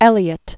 (ĕlē-ət), George Pen name of Mary Ann Evans. 1819-1880.